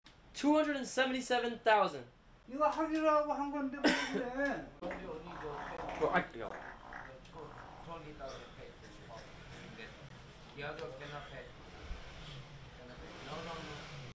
N3on couldn’t stop LAUGHING after